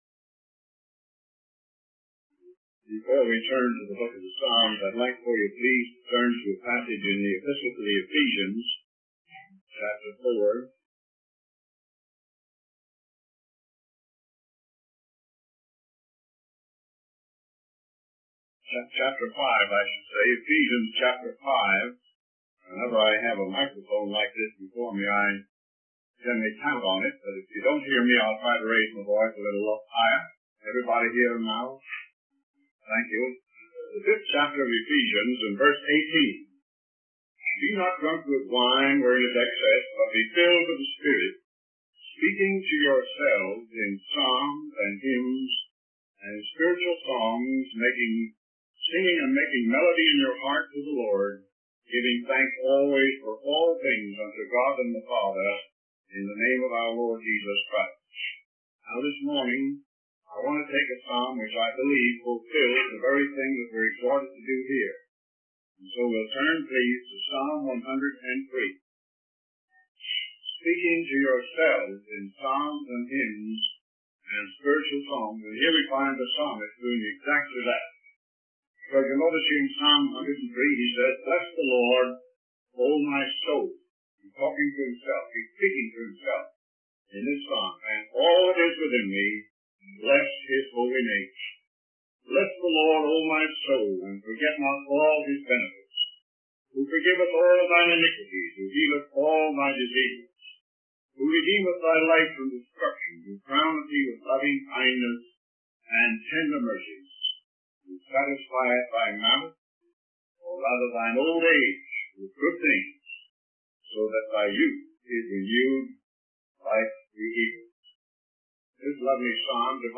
In this sermon, the speaker focuses on the book of Psalms and its division into five sections.